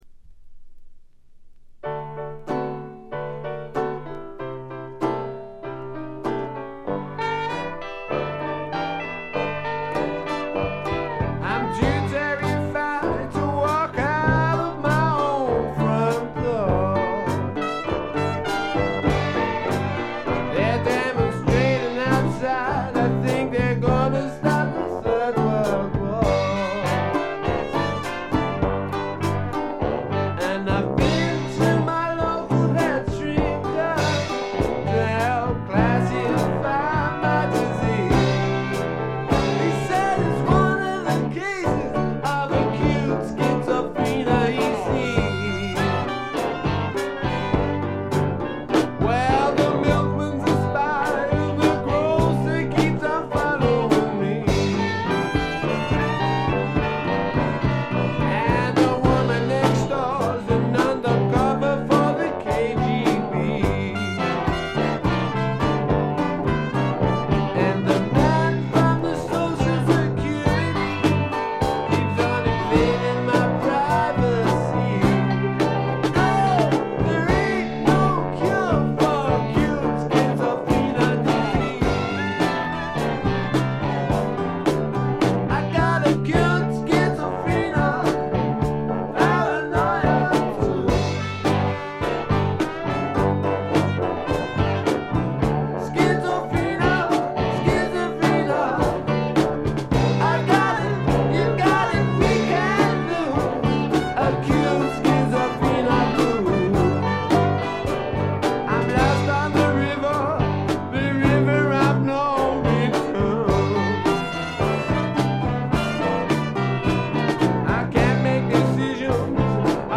これ以外は軽微なバックグラウンドノイズに散発的なプツ音少し。
試聴曲は現品からの取り込み音源です。